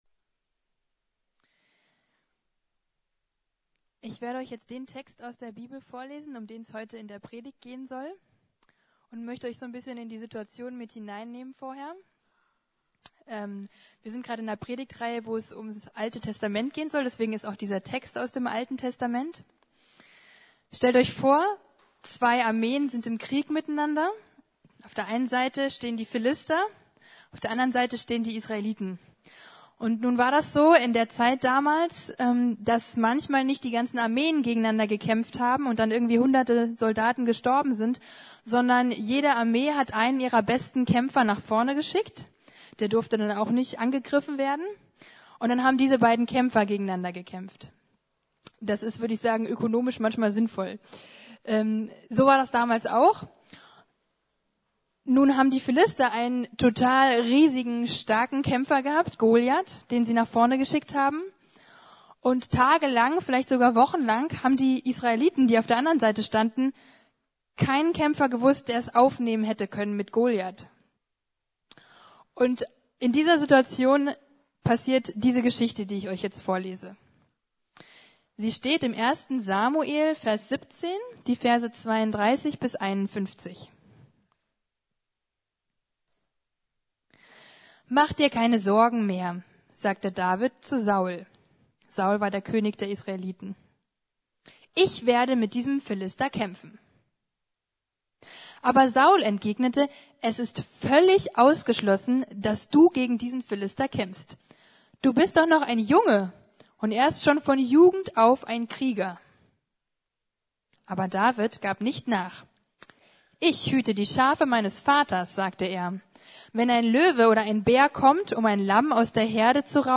Jesus im Alten Testament 7: David und Goliath ~ Predigten der LUKAS GEMEINDE Podcast